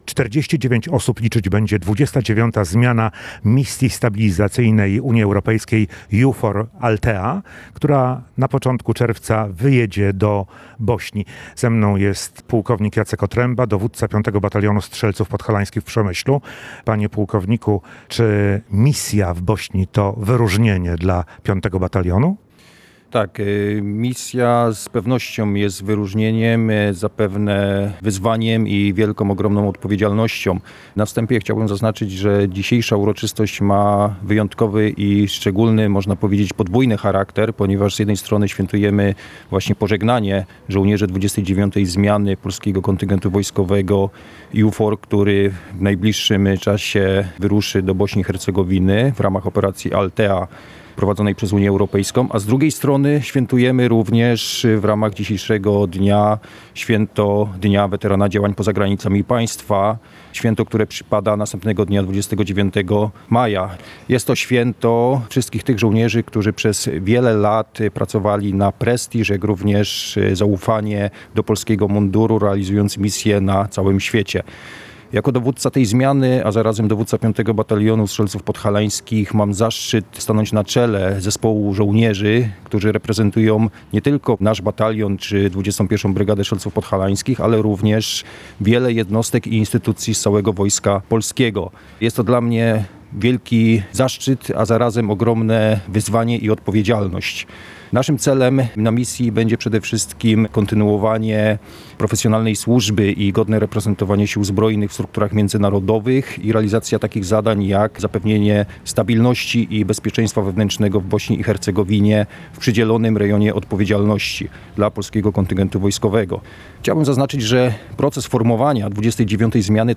W Przemyślu odbyła się uroczystość pożegnania żołnierzy i pracowników resortu obrony udających się na misję stabilizacyjną do Bośni.